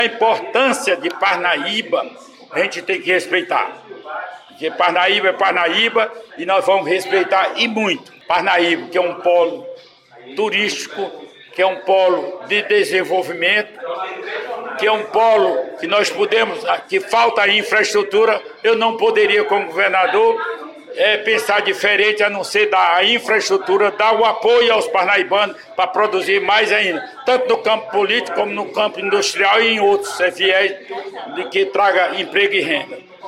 Questionado pela reportagem do Tribuna de Parnaíba, sobre a possibilidade do nome de um parnaibano em sua equipe de gestão em caso de vitória, Dr. Pessoa diz reconhecer a grandeza da cidade e que buscará um nome técnico para dar sua contribuição.